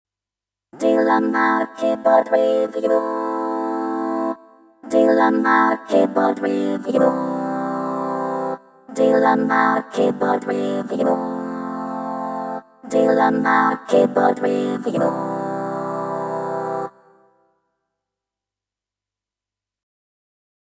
Casio CT-S1000V Test ➤ Das erste Keyboard, das eigene Texte sprechen und singen kann.
Casio CT-S1000V Test Keyboard mit Sprachsynthese